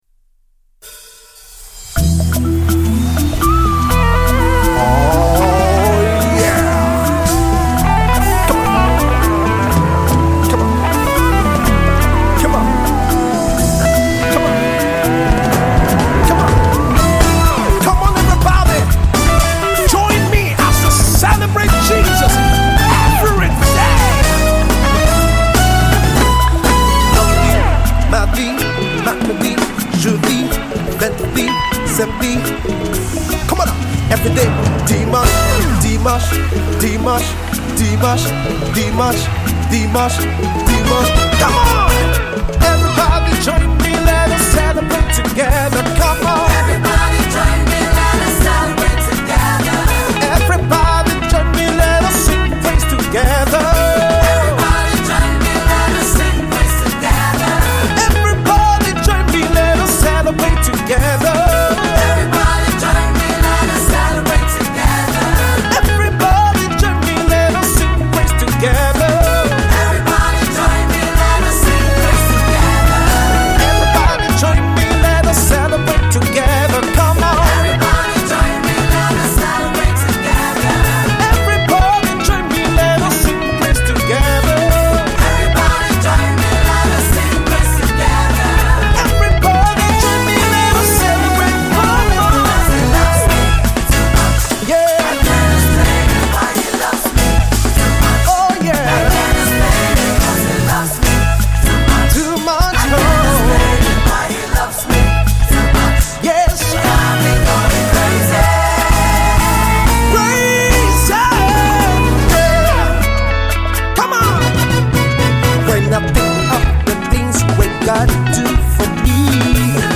Anointed praise and worship leader